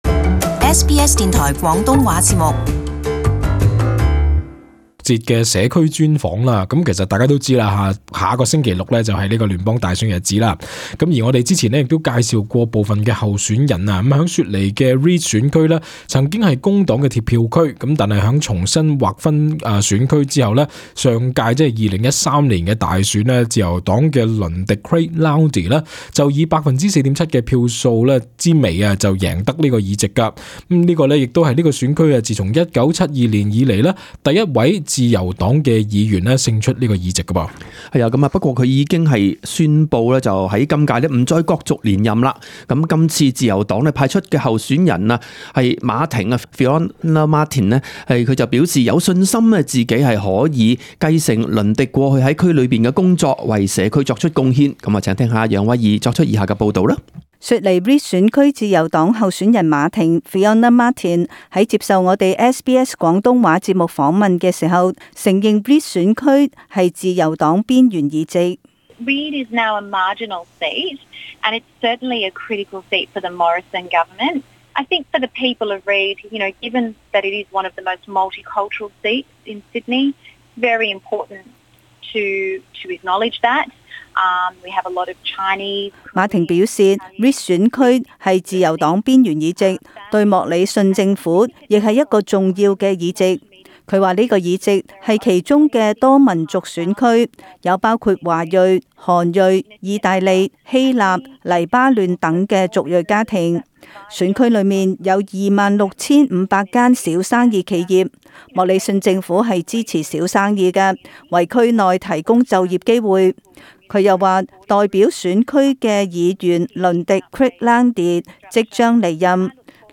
【聯邦大選2019】候選人專訪- Reid選區馬婷